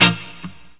RIFFGTR 18-R.wav